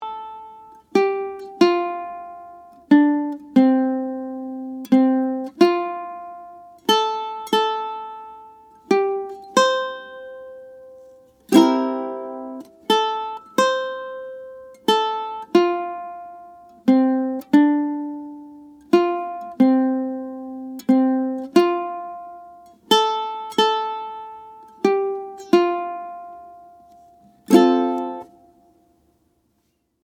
Voicing: Ukulele